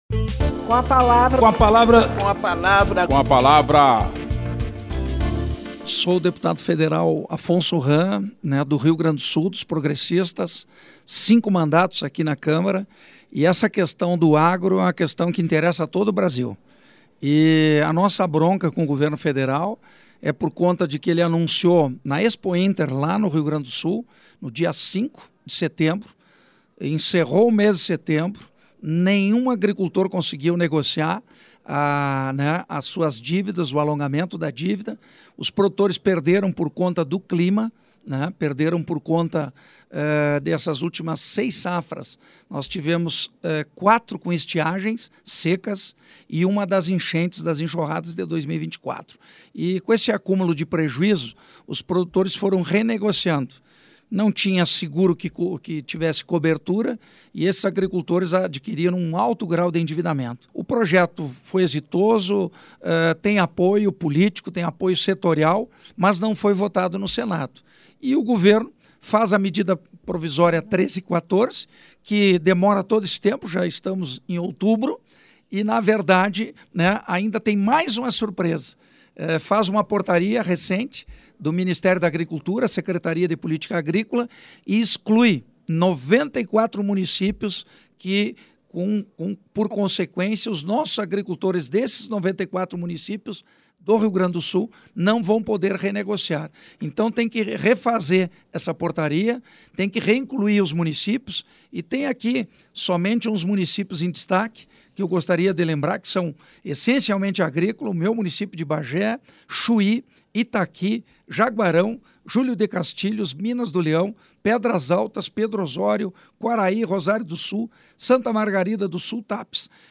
Espaço aberto para que cada parlamentar apresente aos ouvintes suas propostas legislativas